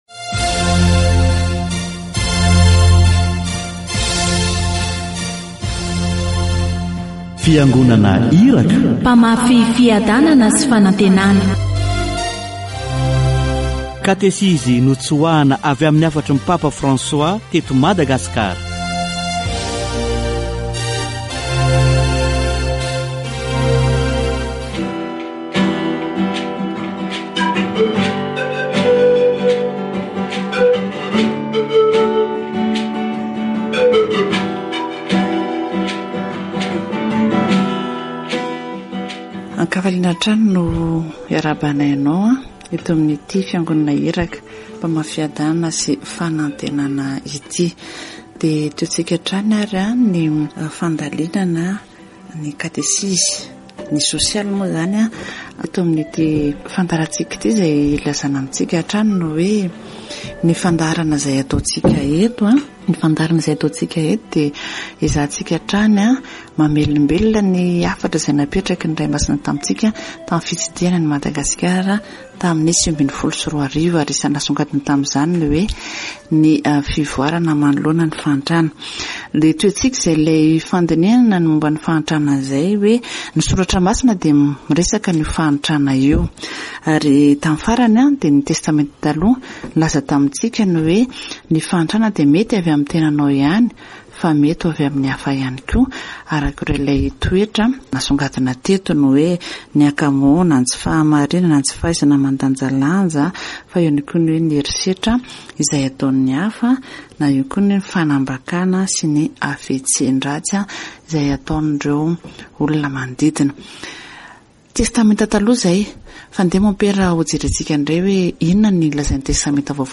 Catechesis on social pastoral care